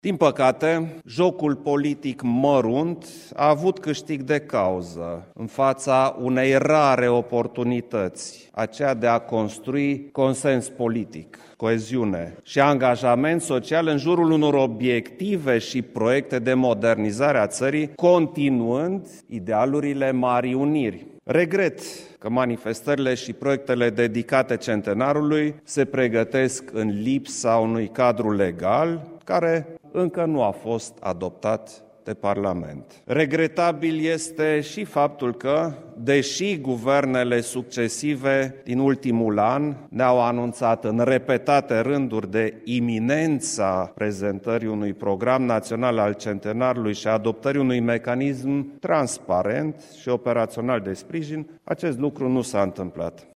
Declarația a fost făcută la dezbaterea „România la Centenar”, organizatã de Administrația Prezidențialã, la Palatul Cotroceni.